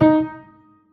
EFlat.wav